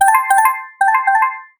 warning_soft.wav